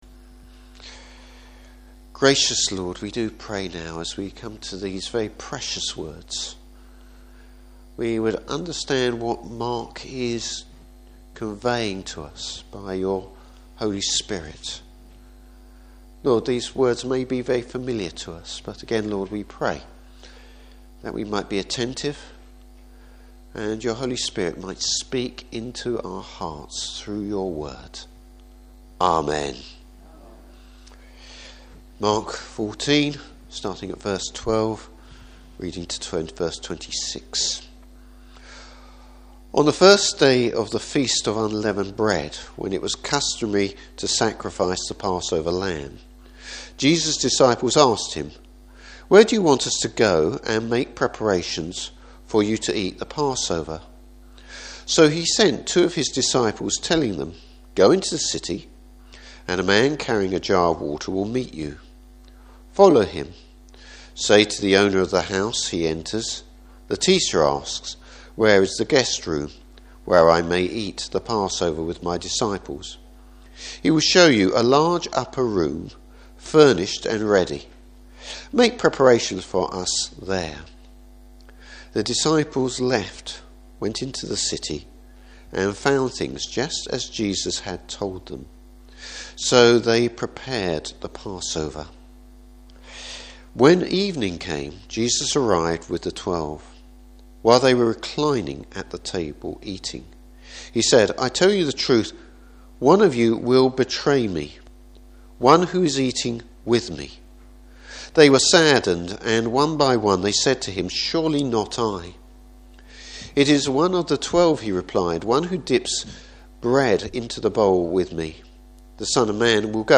Service Type: Morning Service Why is the Lord’s Supper so important?